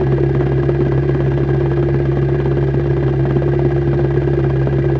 spaceEngineSmall_002.ogg